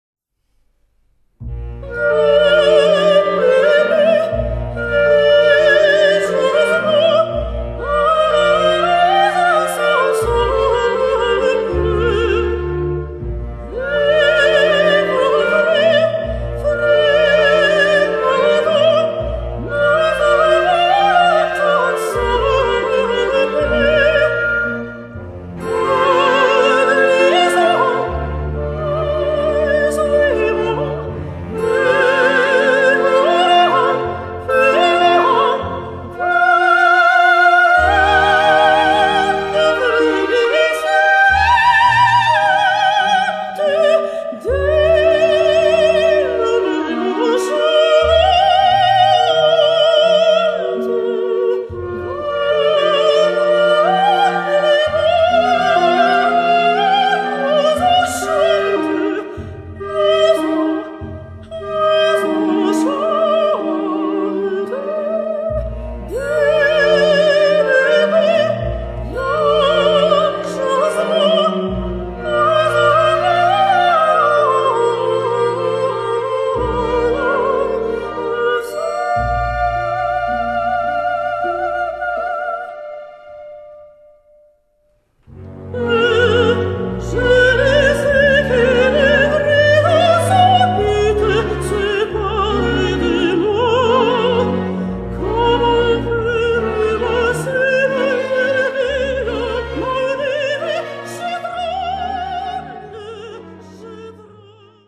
Voicing: Soprano, Clarinet and Orchestra